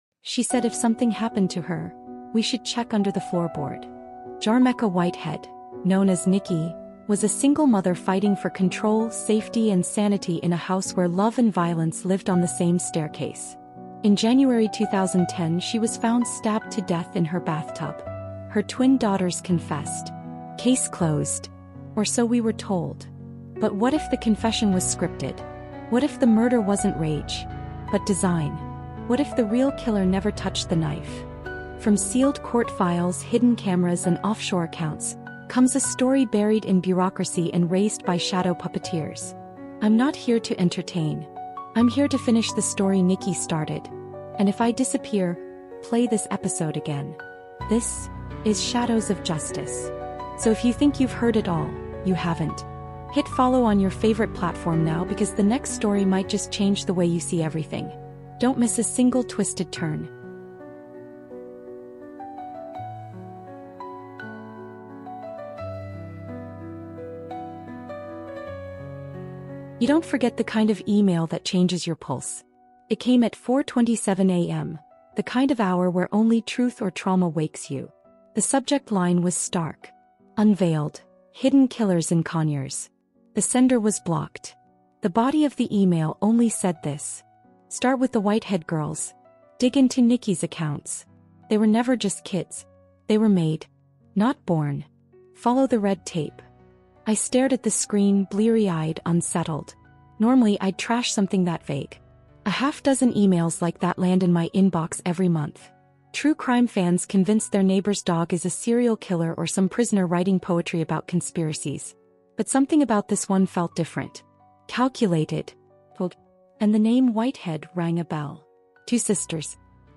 In this gripping, investigative true crime audiobook, a female journalist reopens the case